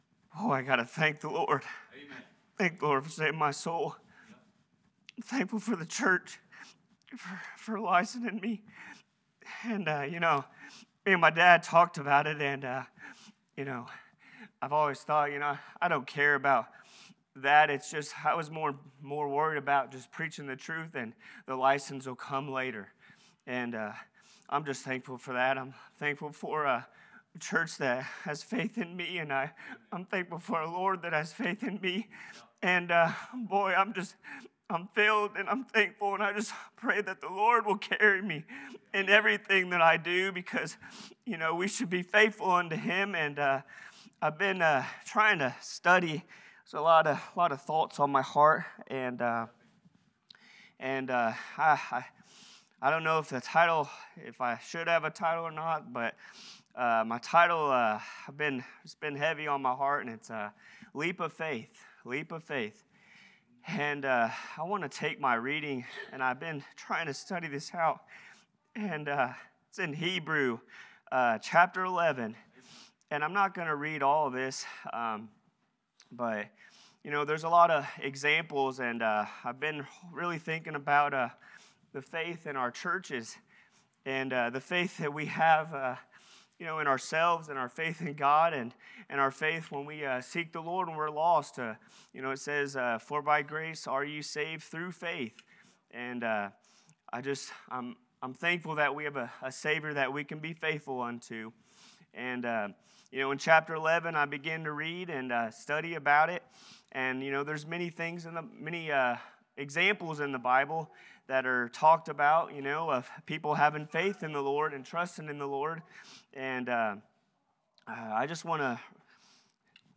2025 Leap of Faith Preacher
Hebrews 12:1 Service Type: Worship « A Godly Home How Can These Things Be?